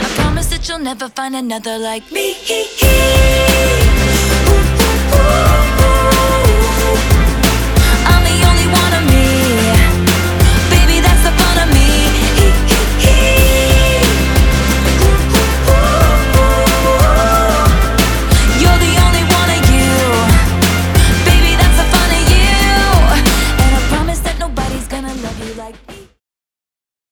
• Качество: 320, Stereo
громкие
женский вокал
заводные
Dance Pop
bubblegum pop